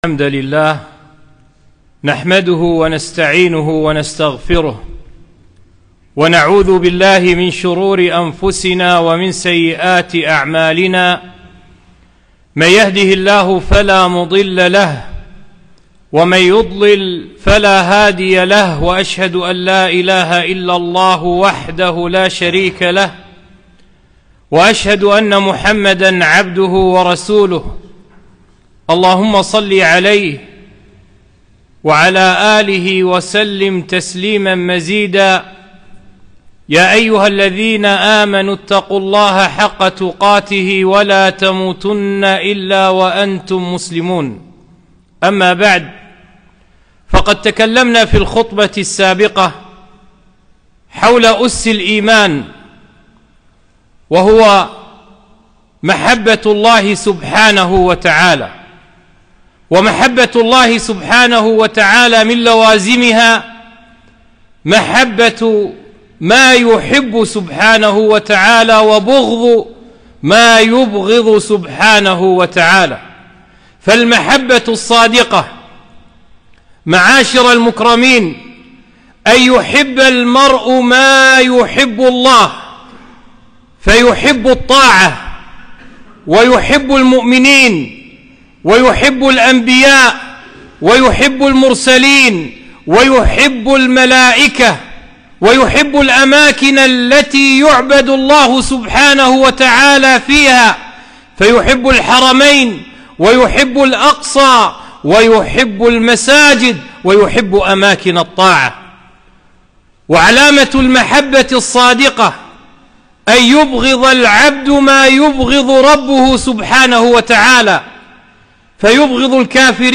خطبة - محبة النبي صلى الله عليه وسلم